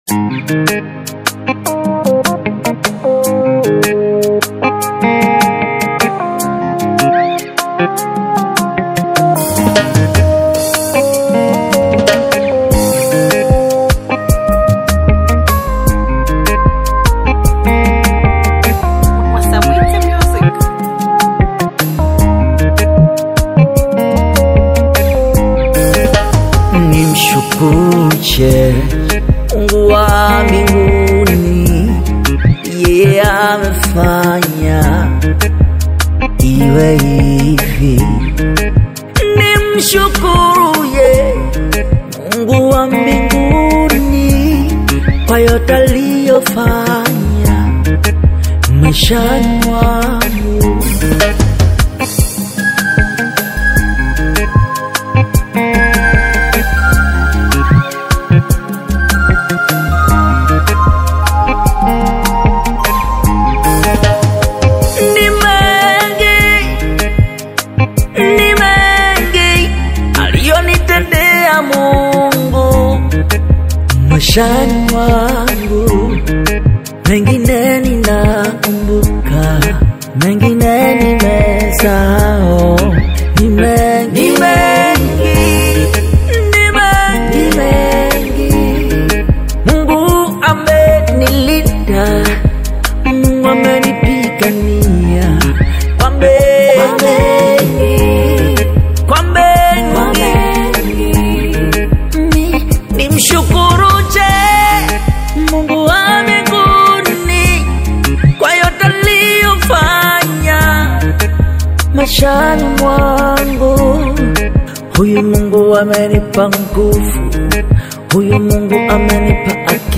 soul-stirring new single
veteran worship leader